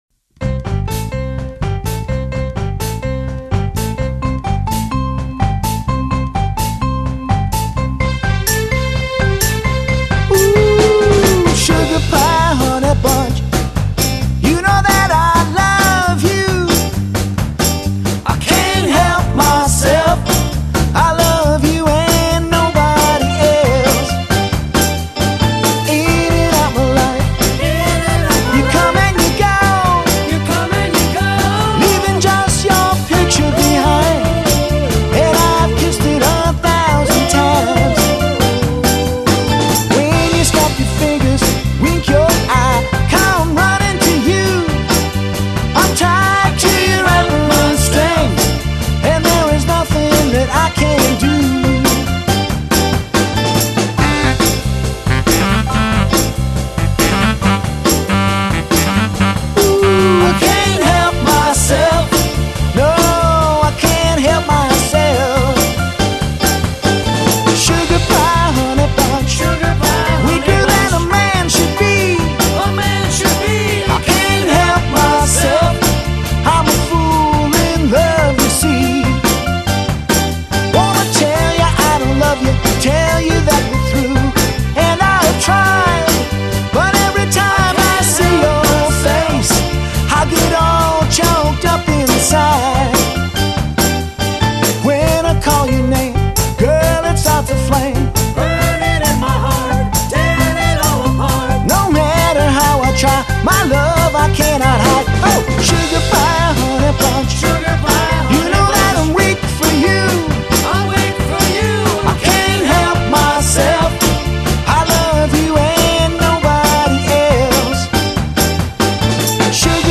studio album, I sing & play